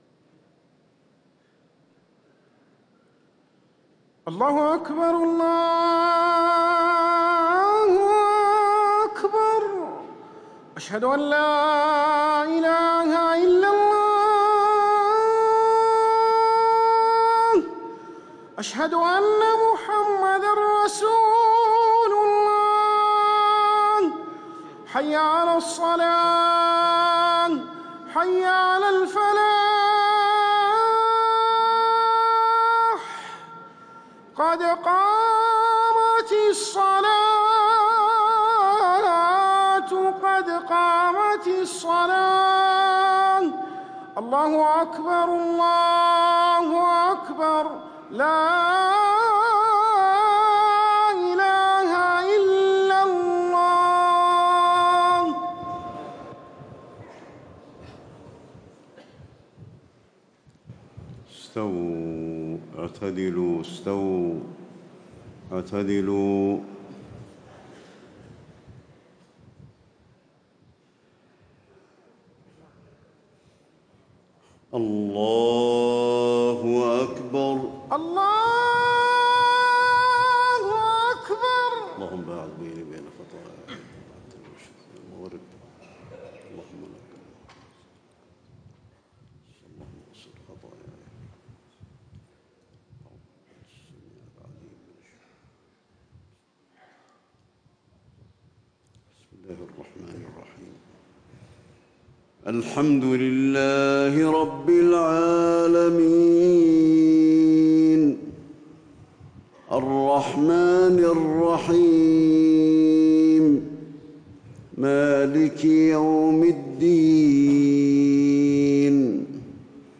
صلاة الفجر 1 شوال 1437هـ سورة ق > 1437 🕌 > الفروض - تلاوات الحرمين